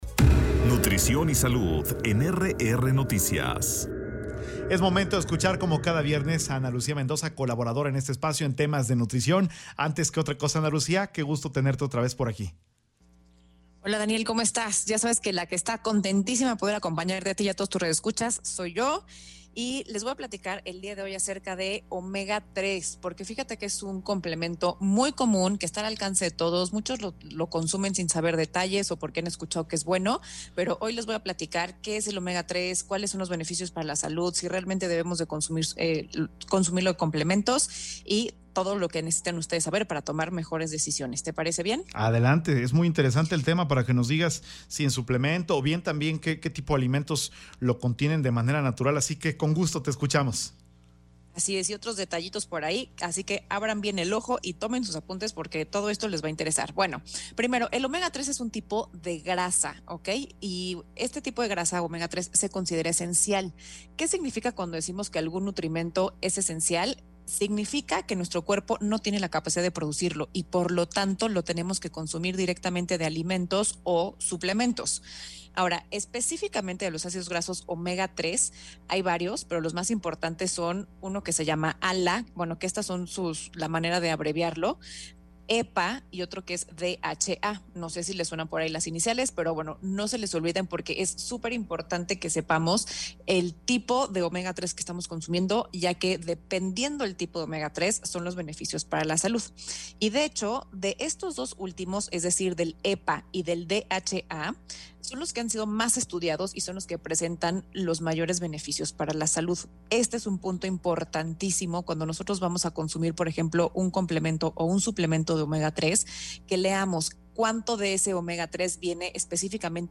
NUTRIOLOGA.mp3